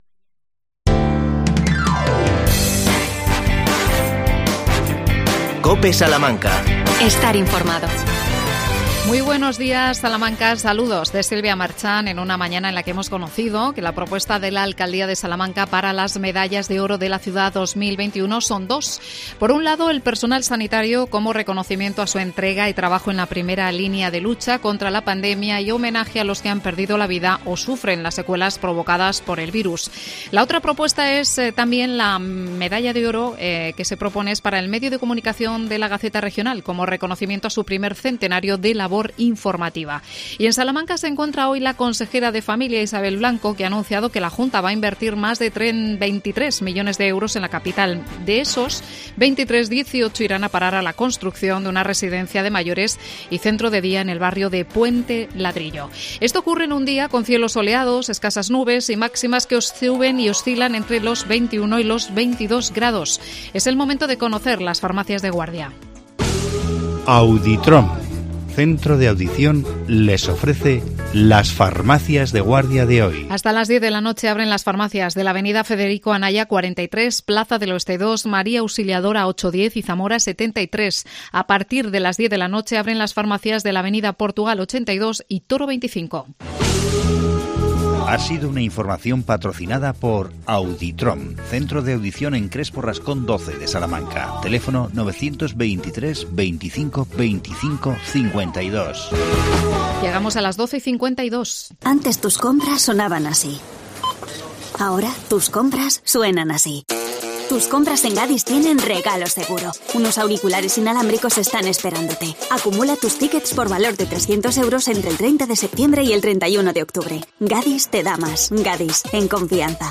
AUDIO: Entrevista al concejal de Tráfico en el Ayuntamiento de Salamanca Fernando Carabias.